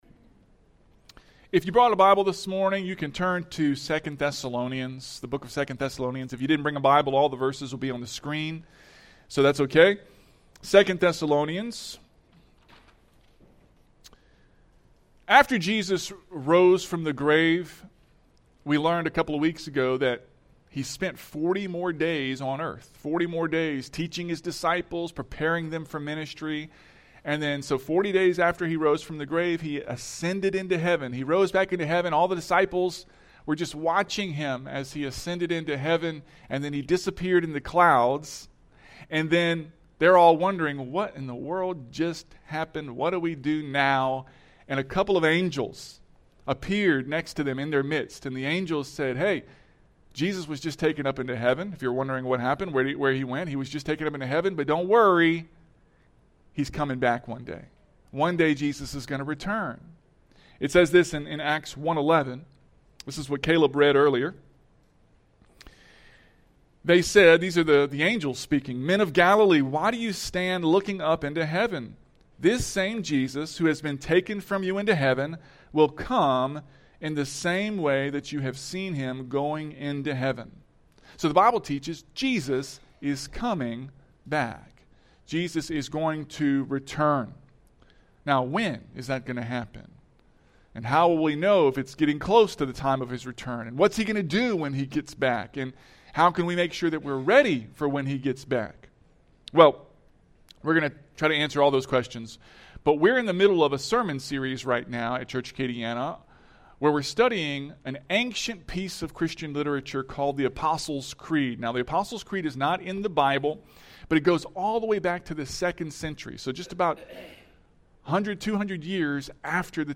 The Apostles’ Creed – Week 8 Sermon